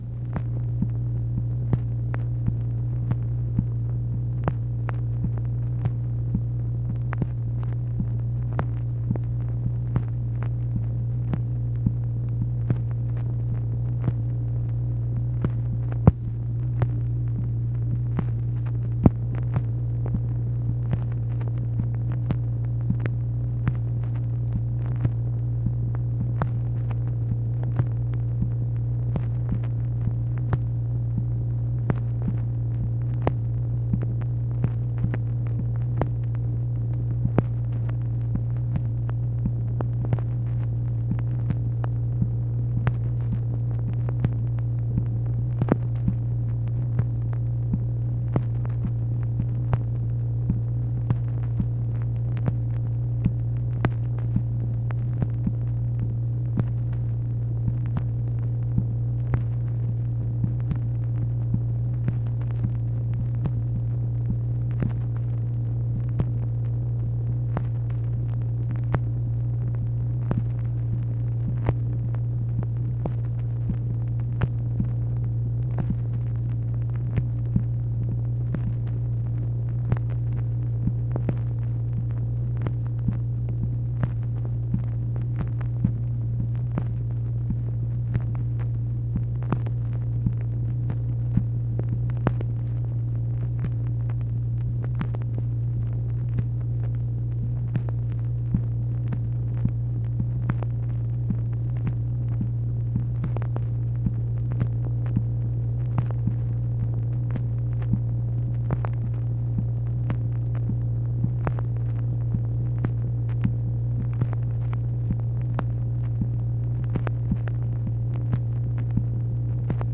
MACHINE NOISE, February 7, 1964